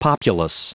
Prononciation du mot populace en anglais (fichier audio)
Prononciation du mot : populace